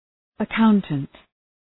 Προφορά
{ə’kaʋntənt}